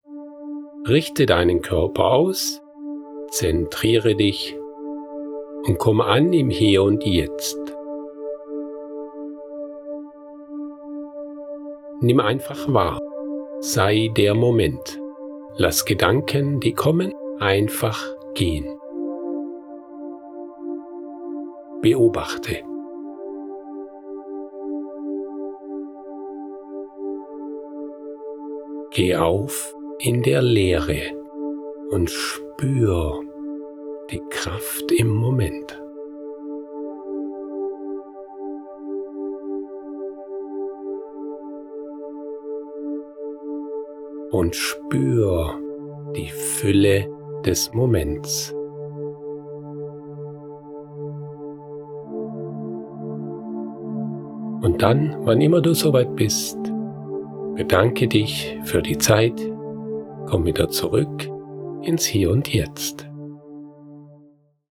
Kurzmeditation Achtsamkeit
Übung-1-Minute-Achtsamkeitstraining.wav